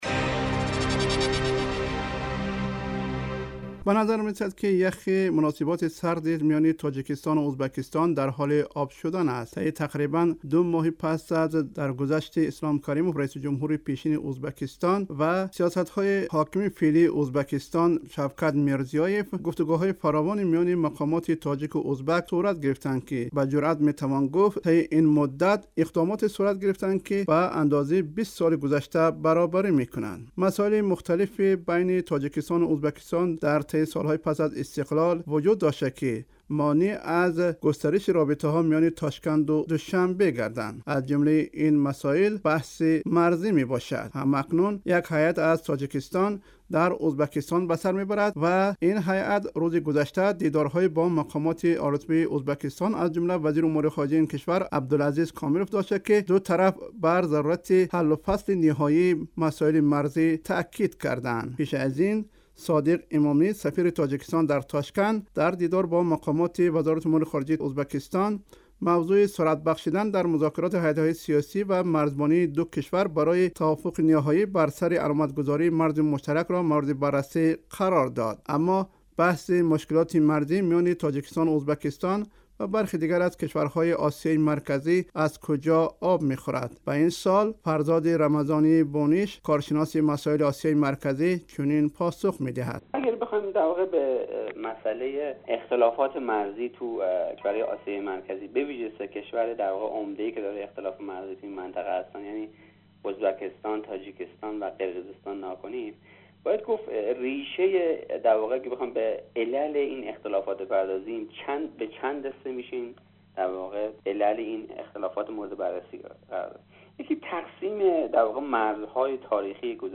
Гузориши ҳамкорамон